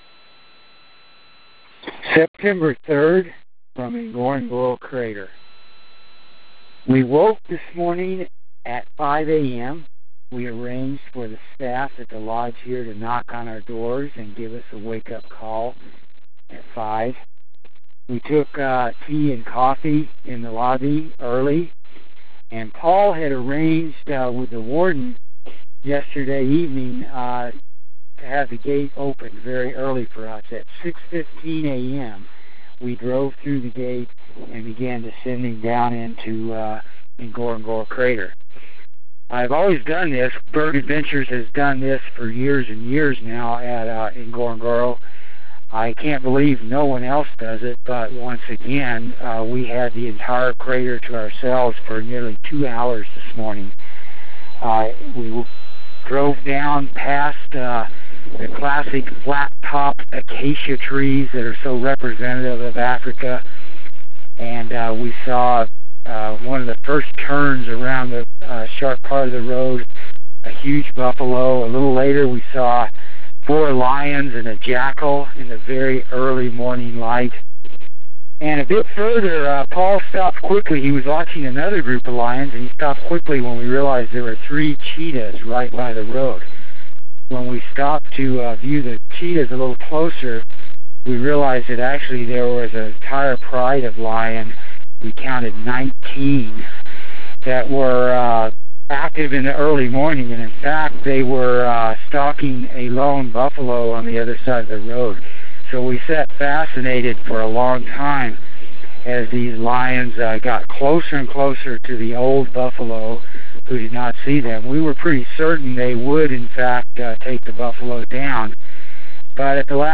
September 3 – All alone in Ngorongoro Crater